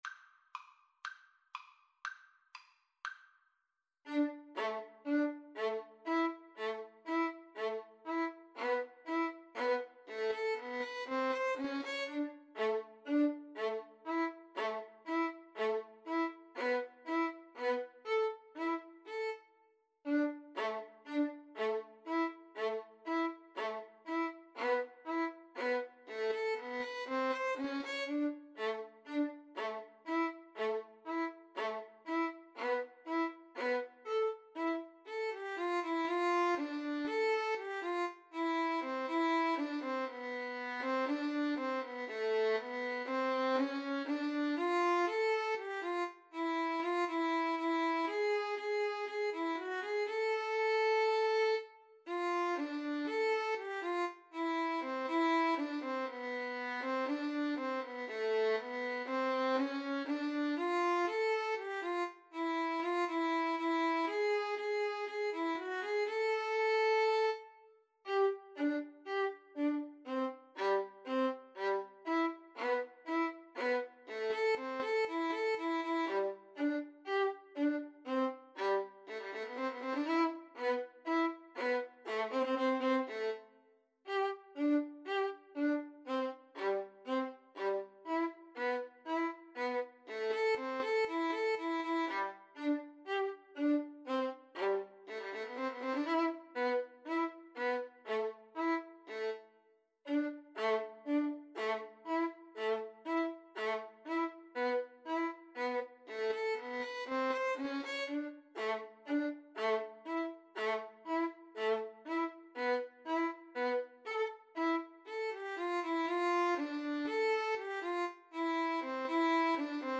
A minor (Sounding Pitch) (View more A minor Music for Violin Duet )
Fast Two in a Bar =c.120